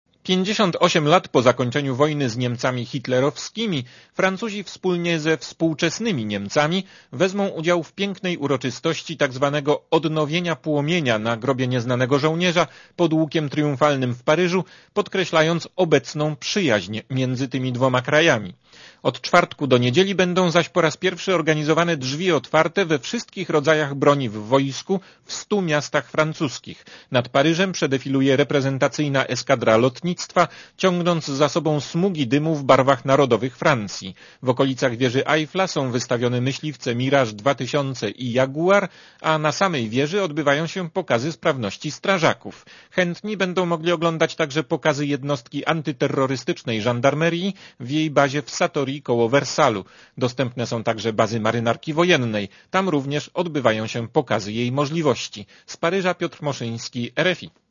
Korespondencja z Paryża (225Kb)